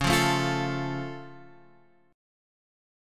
C#+ chord